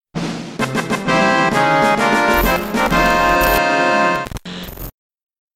gliiitch.mp3